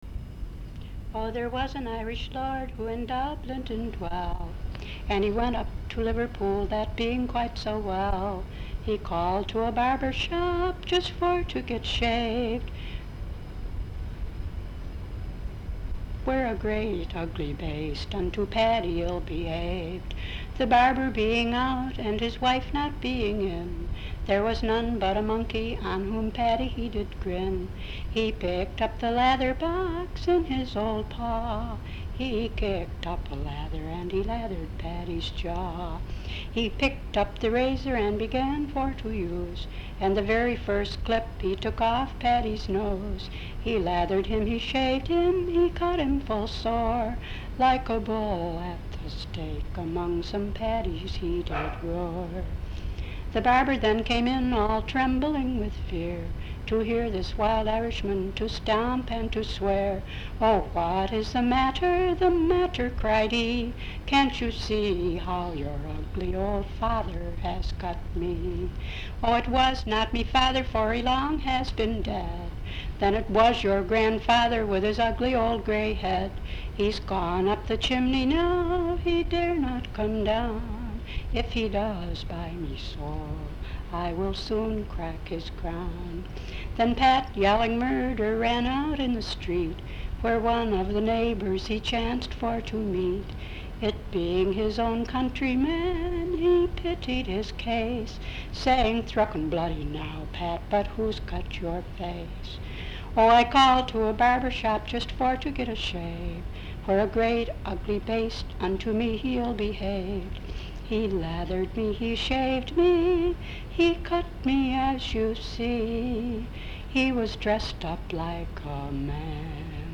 Folk songs, English--Vermont (LCSH)
sound tape reel (analog)